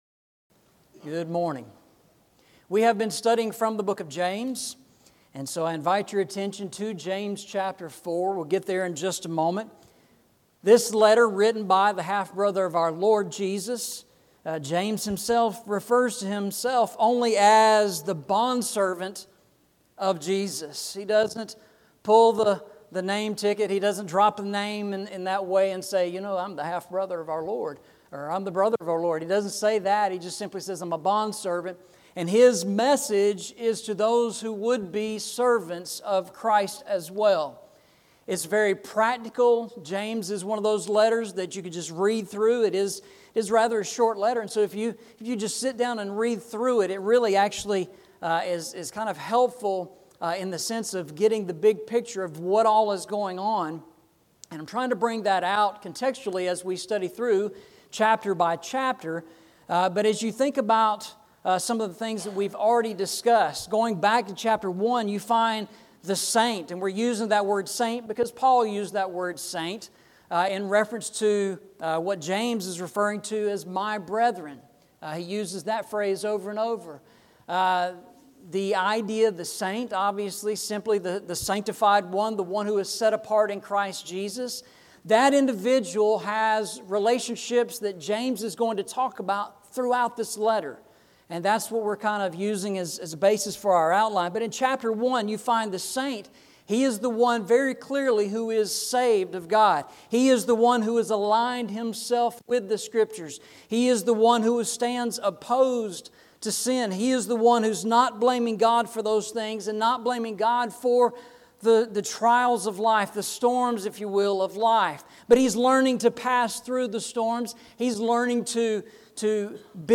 Eastside Sermons Passage: James 4:1-3 Service Type: Sunday Morning « The Holy Lands